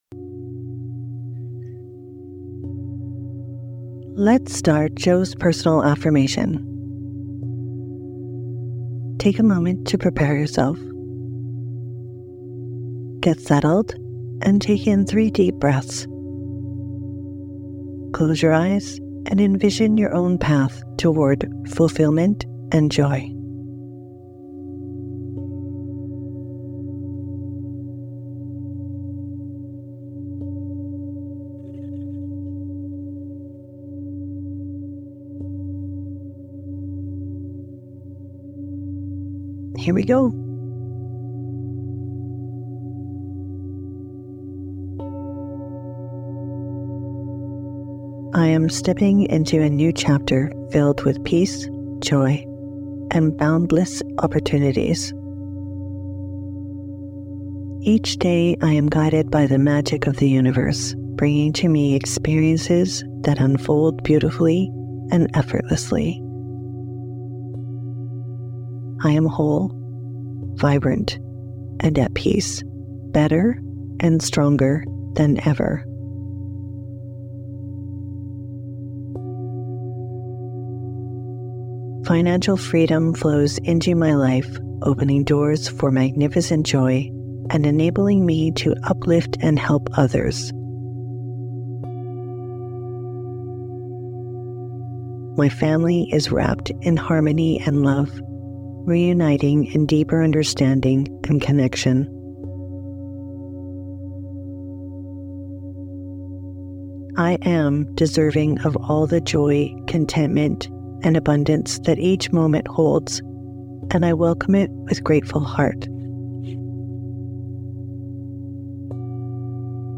This a sleep version intended to be used to create repetition where the affirmation repeats three times to create an anchor into the subconscious and gently guide you into a peaceful sleep.